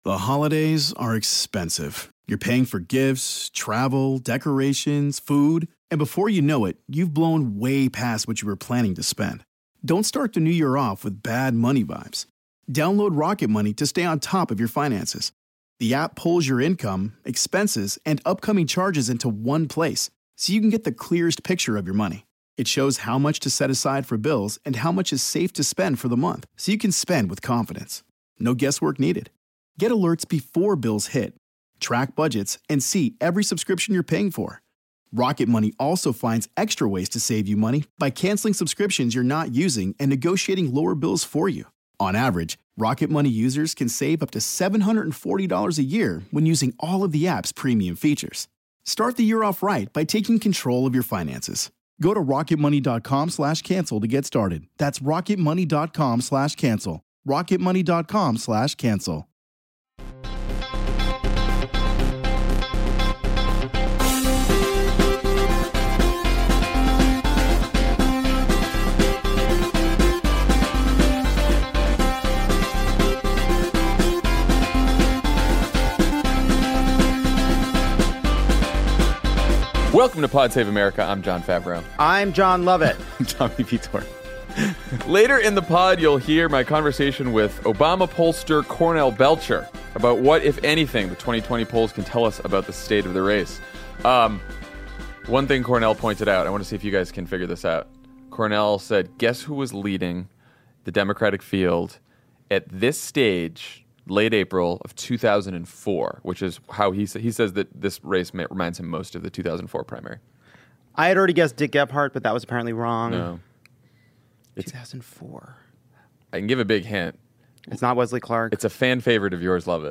Another white nationalist targets a synagogue in California, voters believe Trump is guilty of obstruction but aren’t ready for impeachment, and Beto O’Rourke rolls out the first detailed climate plan of the 2020 campaign. Then former Obama pollster Cornell Belcher talks to Jon F. about the state of the race, and what Democratic voters are looking for.